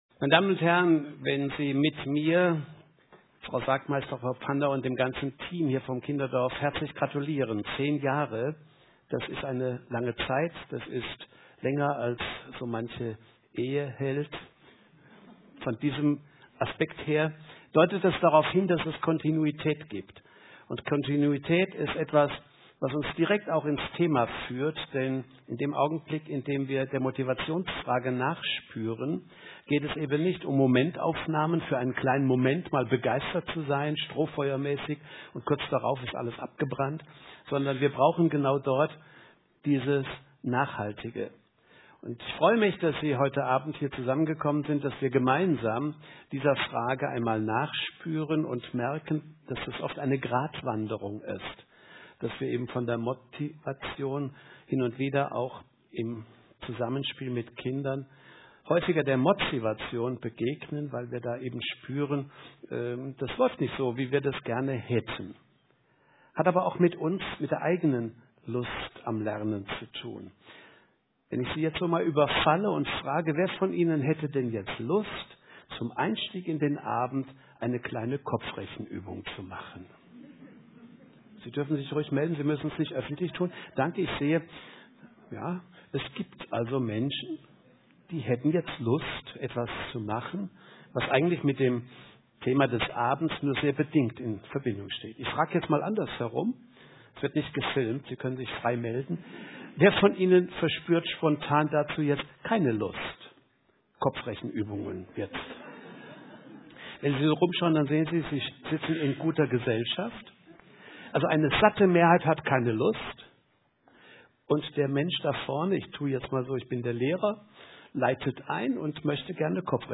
Vortrag „So macht Lernen Spaß!“